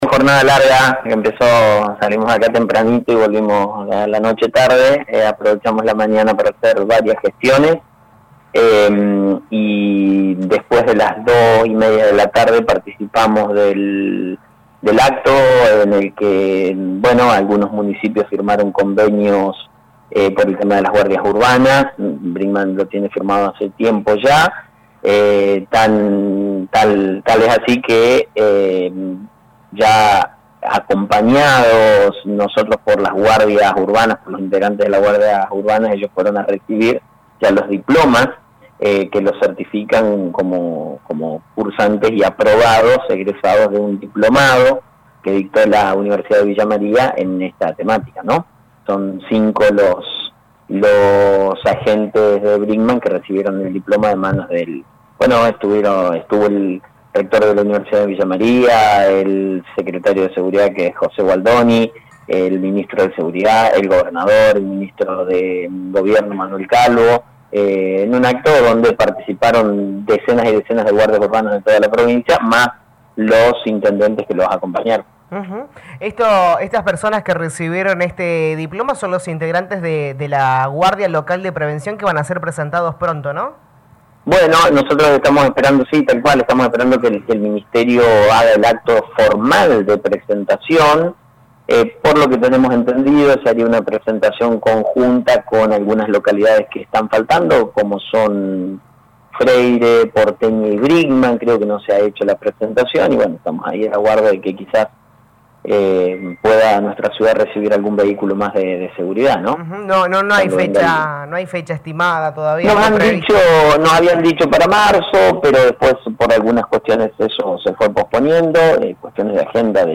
En diálogo con LA RADIO 102.9 FM el intendente Municipal Lic. Mauricio Actis explicó que en los próximos días se presentará la Guardia Local de Prevención.